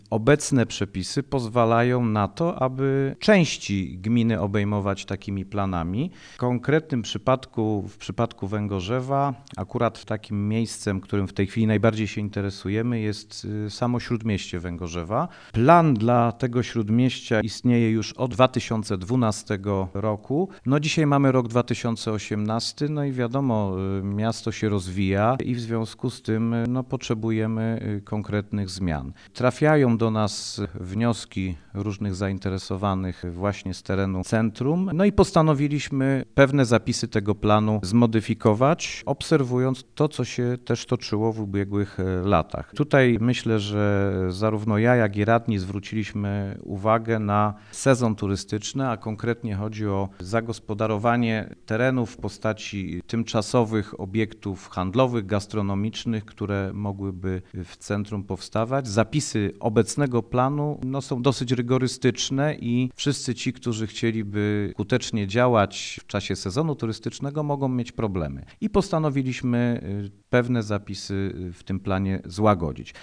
Zapisy obecnego planu są zbyt rygorystyczne, zatem urzędnicy planują pójść branżowcom na rękę i nieco uprościć zasady działalności w śródmieściu, mówi Krzysztof Piwowarczyk, burmistrz Węgorzewa.